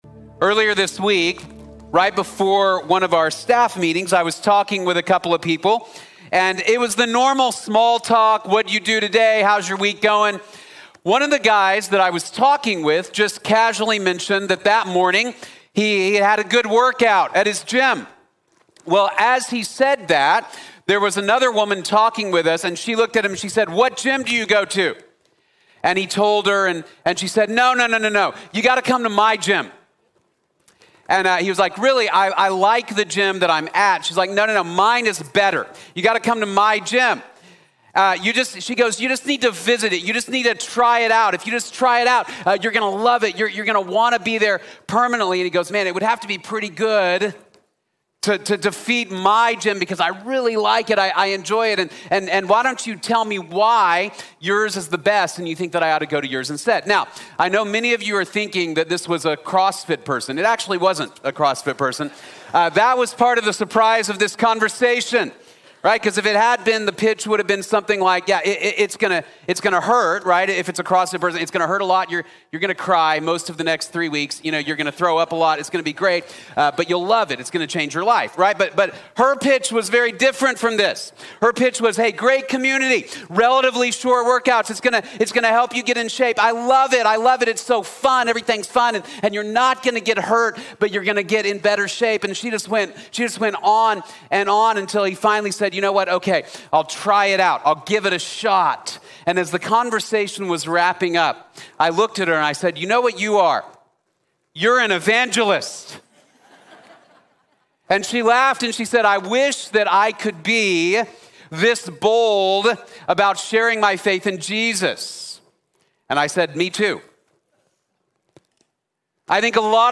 Taking Jesus to the World | Sermon | Grace Bible Church